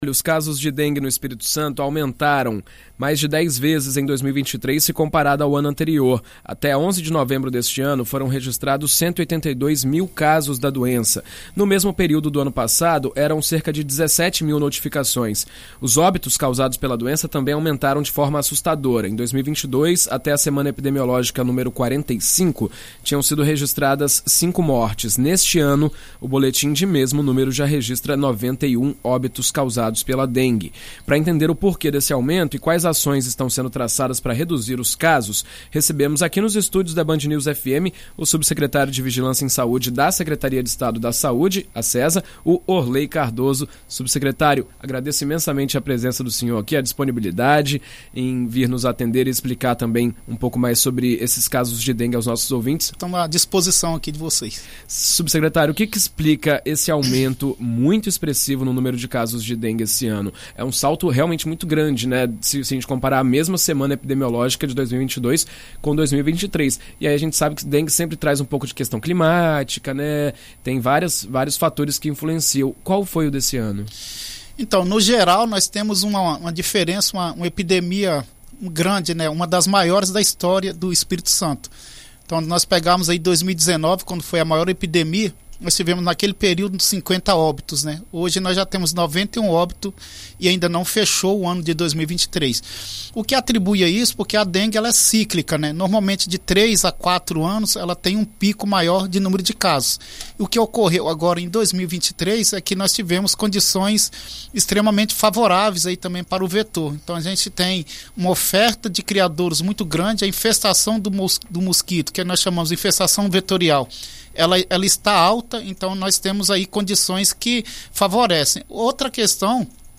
Em entrevista à BandNews FM ES, o subsecretário de Vigilância em Saúde da Sesa, Orlei Cardoso, explicou as causas do aumento expressivo
Para entender o porquê desse aumento e quais ações estão sendo traçadas para reduzir os casos, o subsecretário de Vigilância em Saúde da Secretária de Estado da Saúde (Sesa), Orlei Cardoso, foi entrevistado na rádio BandNews FM ES nesta terça-feira (28).